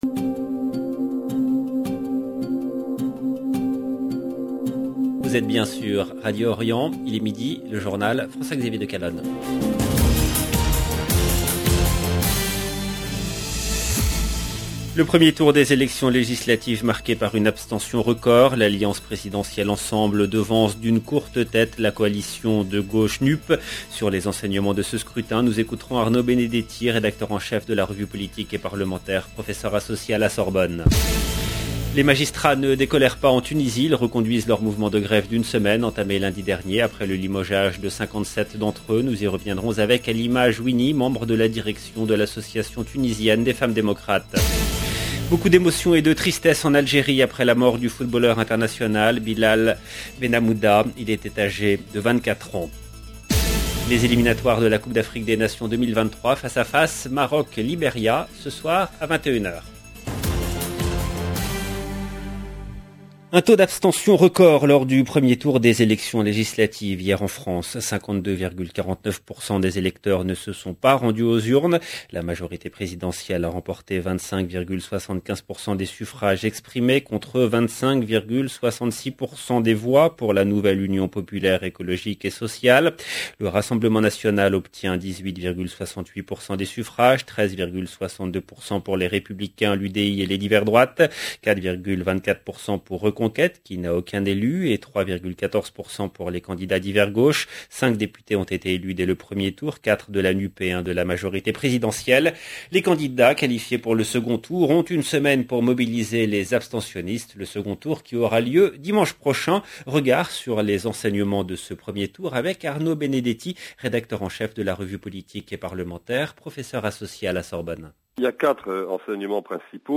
LE JOURNAL EN LANGUE FRANCAISE DU 13/06/22 LB JOURNAL EN LANGUE FRANÇAISE